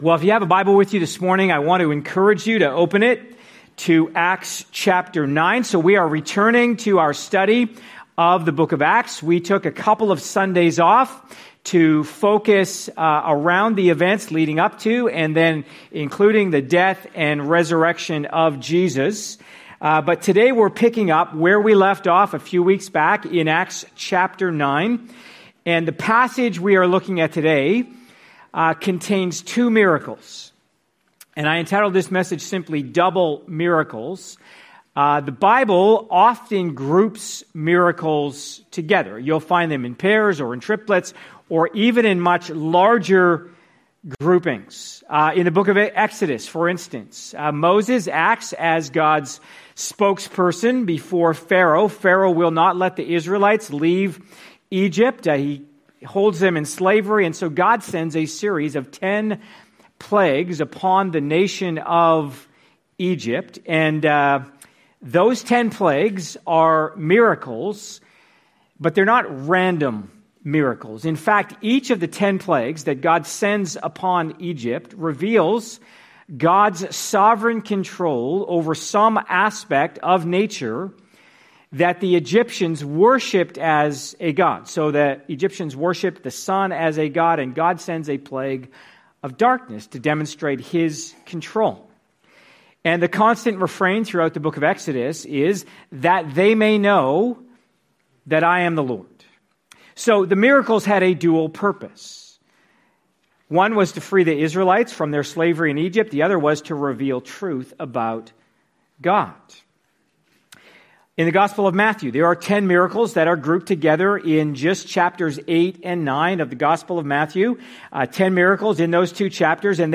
Part of our series, ACTS: Mission & Message (click for more sermons in this series).